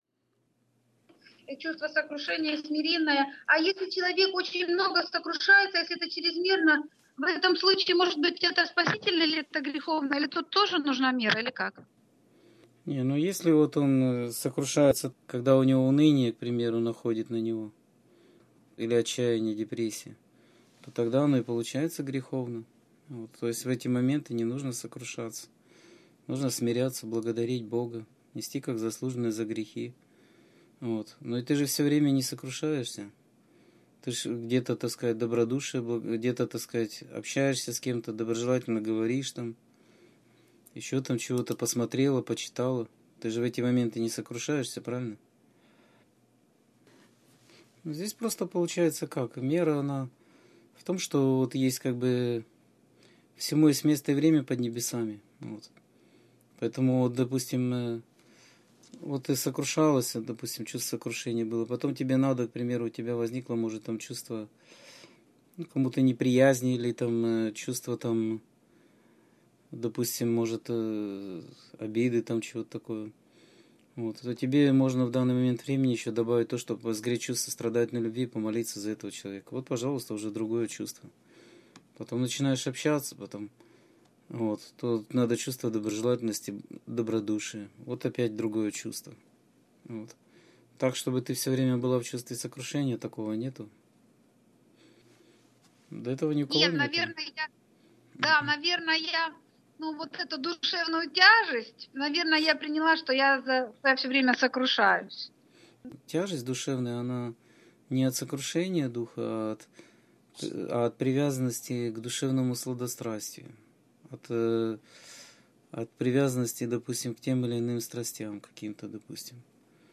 О смысле каждения, символов (Скайп-беседа 12.04.2014) — ХРИСТИАНСКАЯ ЦЕРКОВЬ